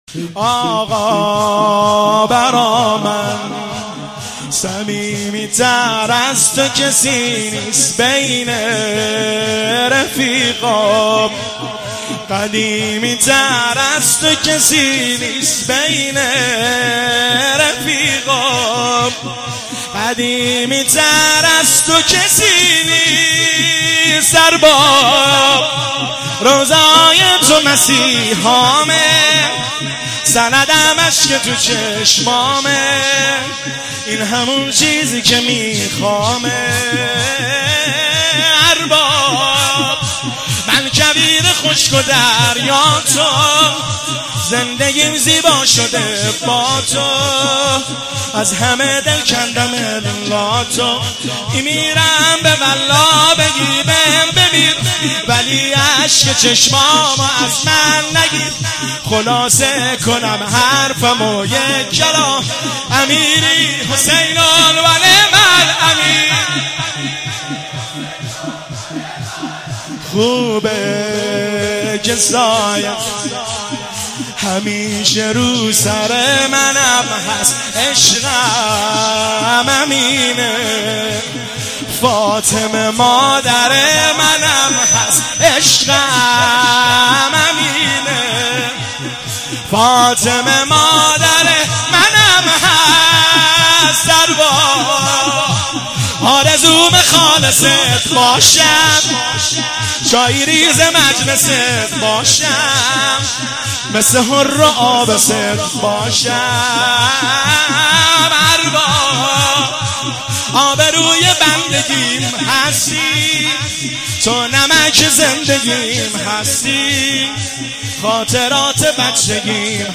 مداحی فاطمیه
شب اول فاطمیه 1397 هیئت بین الحرمین تهران